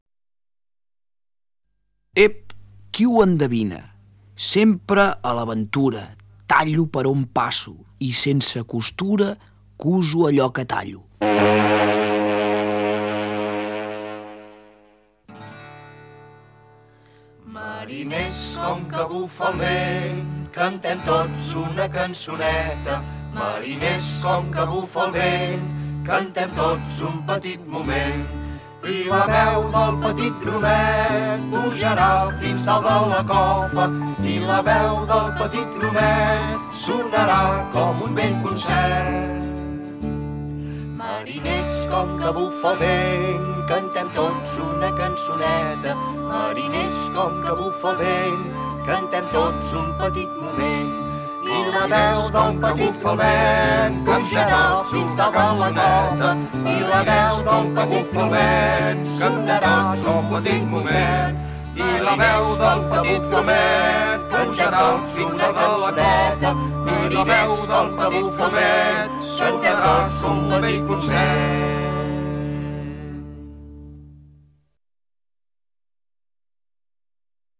Dansa pirata: